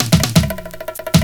FILL 8    -R.wav